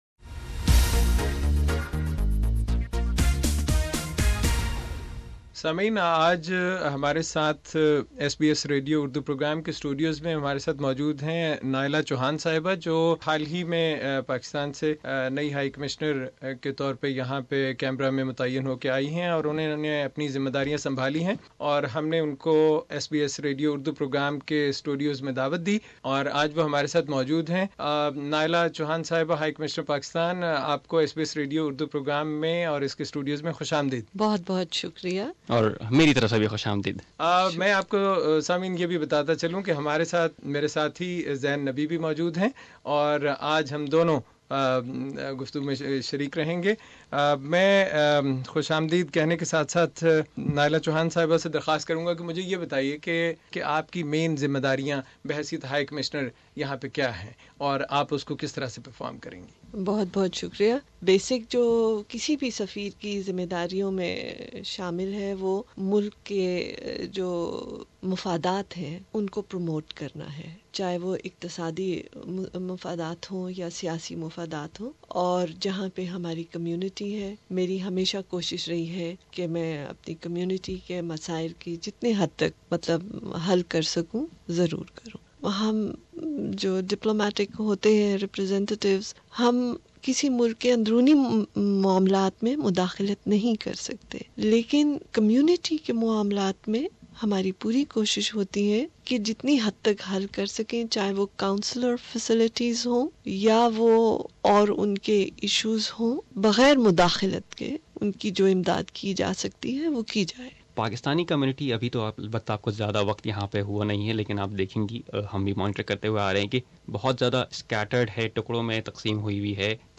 Pakistan High Commissioner Ms Naela Chohan has recently taken charge of her post. SBS Urdu hosted her for an exclusive interview and discussion about the community and the issues it faces.